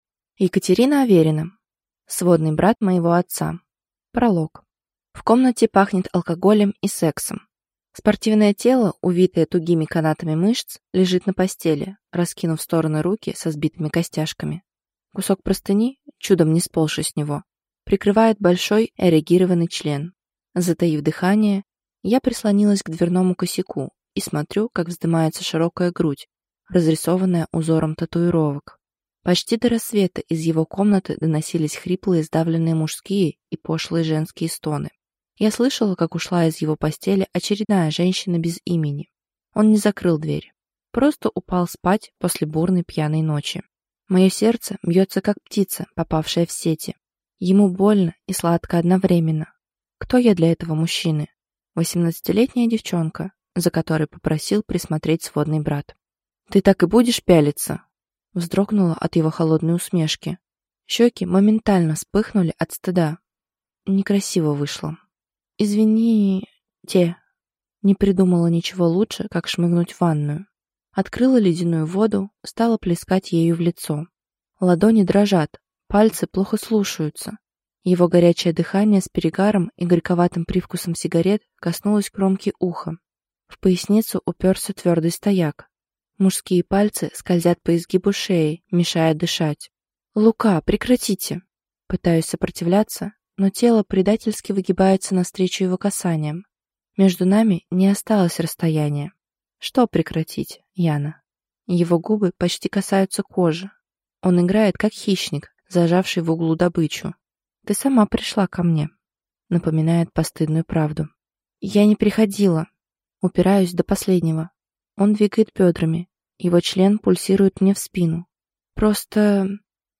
Аудиокнига Сводный брат моего отца | Библиотека аудиокниг